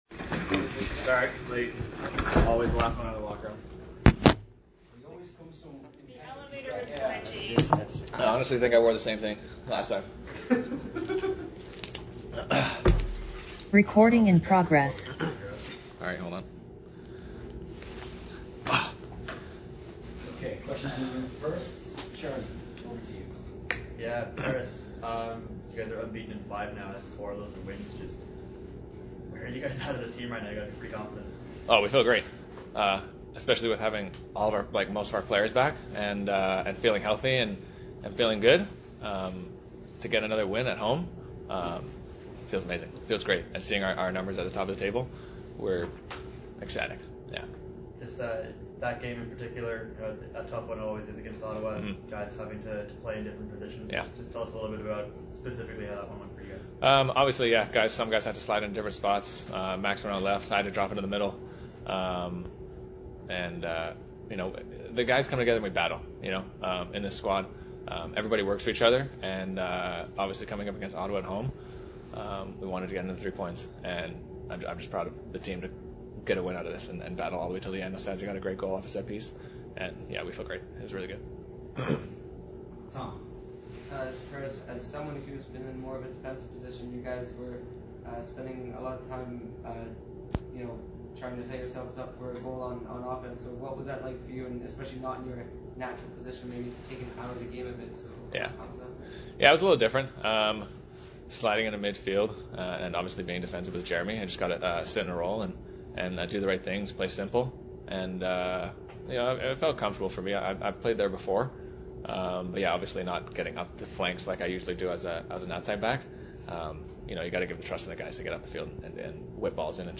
All the audio from the post game press conference after the CPL (regular season game) game